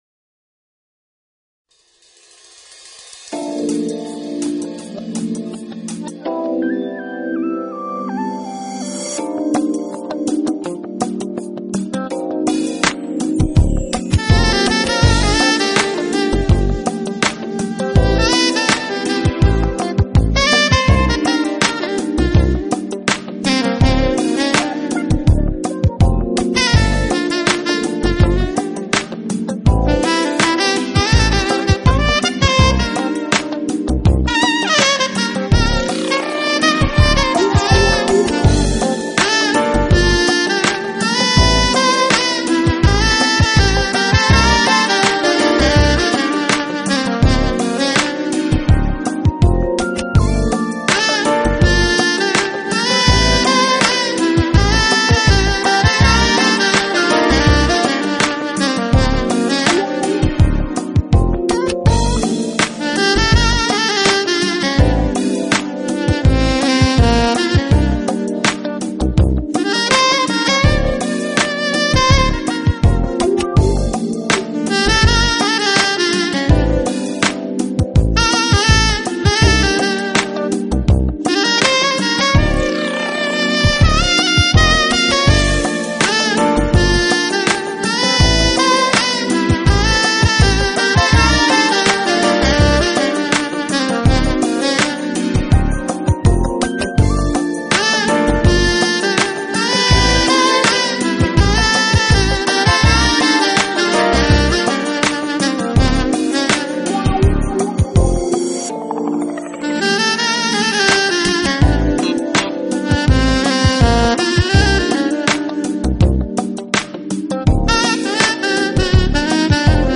他的曲风除了承袭Urban Jazz风格 之外另外受到R&B曲风的影响也相当大。